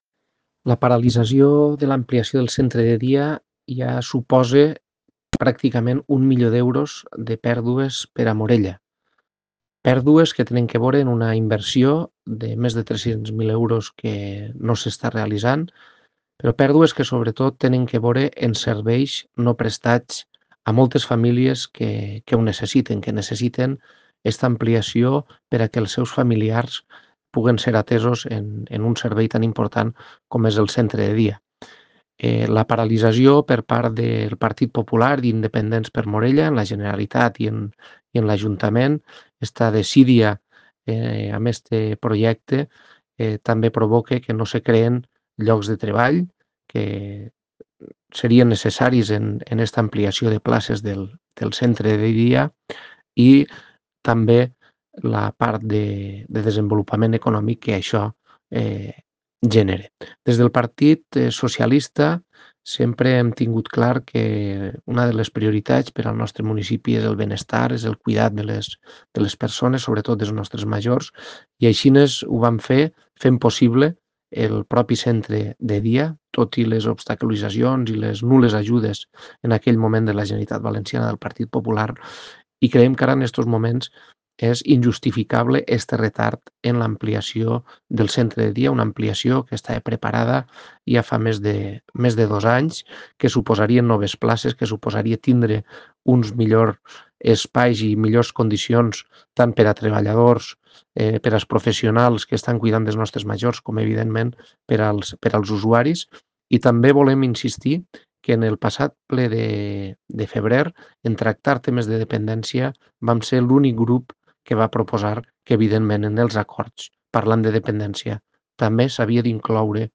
La paralització i retard d´aquesta ampliació tan important per a Morella acumula prop d´1 milió d´euros en perjudici per al nostre poble i sobretot per a la cura i benestar dels nostres veïns“, incideix el portaveu socialista, Rhamsés Ripollés.
DECLARACIONS-RHAMSES-RIPOLLES-PARALITZACIO-CENTRE-DE-DIA-MORELLA-1-MILIO-EUROS.ogg